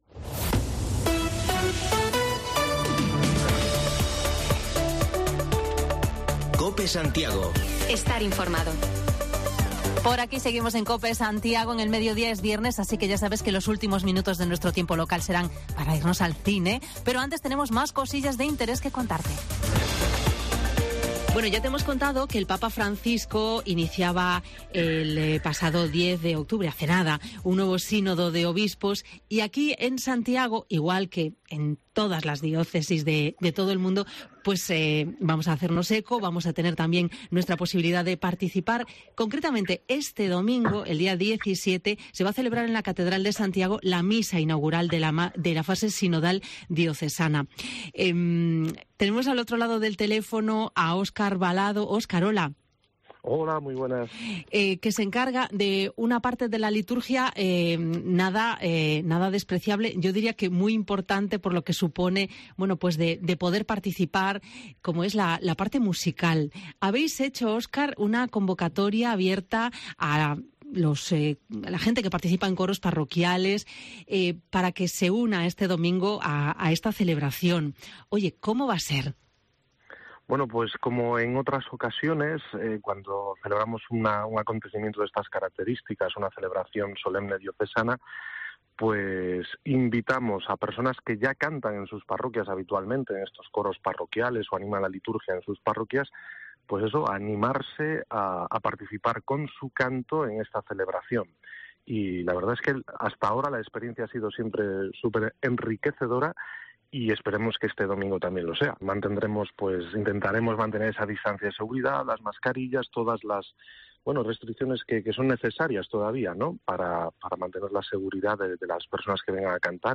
Redacción digital Madrid - Publicado el 15 oct 2021, 14:47 - Actualizado 18 mar 2023, 02:39 1 min lectura Descargar Facebook Twitter Whatsapp Telegram Enviar por email Copiar enlace Hablamos de los preparativos del Sínodo Diocesano y nos acercamos hasta Ordes, para repasar la actualidad municipal en conversación con su alcalde